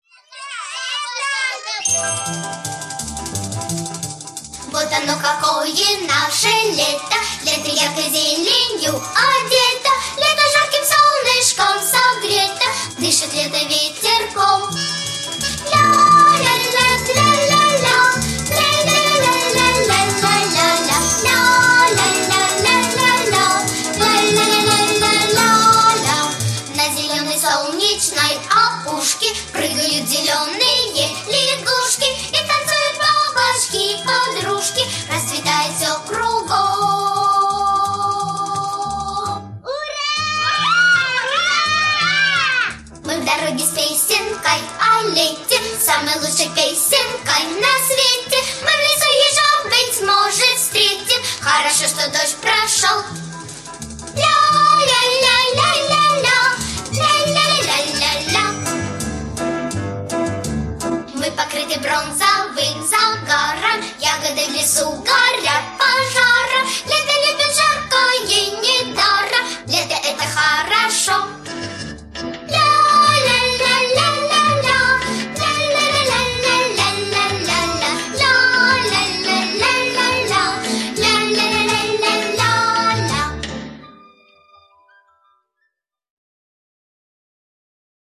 динамичная, танцевальная, яркая.